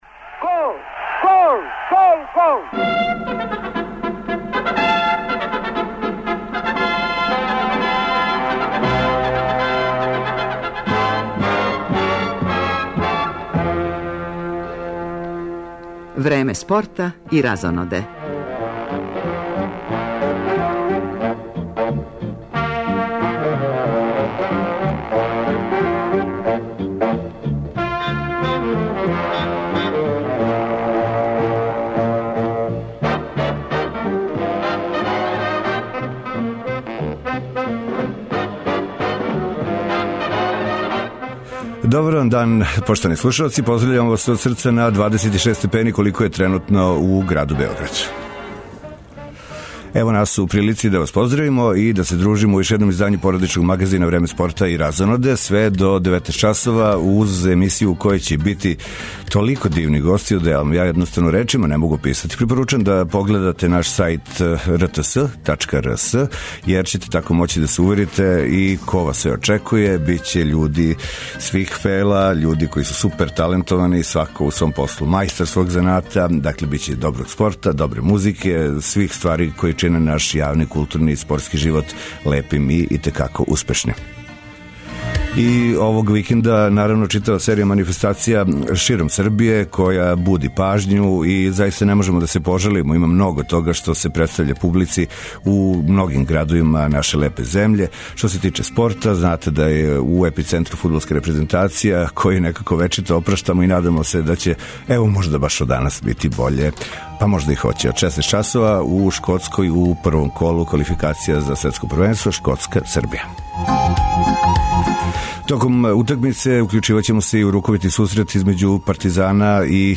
Рукометаши Партизана започињу квалификациони турнир за Лигу шампиона, први ривал данас им је Порто - са овог меча имаћемо јављања репортера.